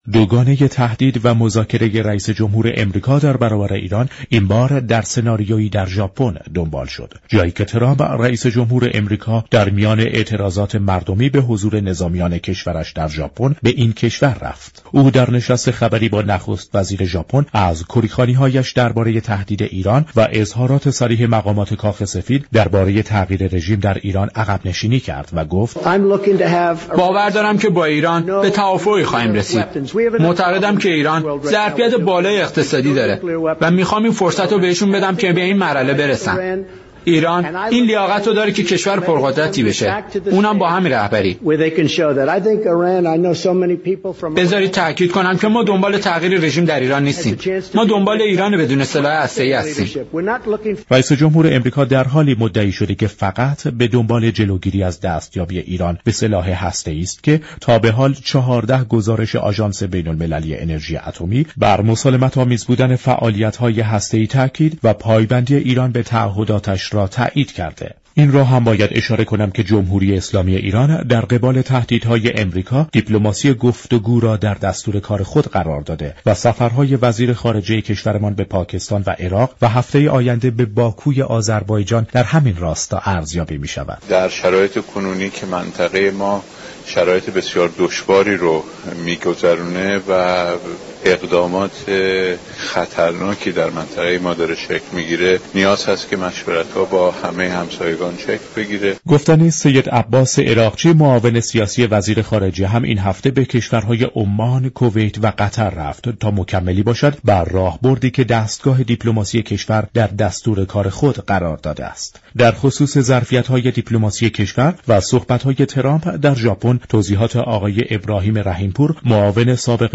برنامه رویدادهای هفته روزهای جمعه ساعت 14:30 از رادیو ایران پخش می شود. این گفت و گو را در ادامه می شنوید.